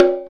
percussion 52.wav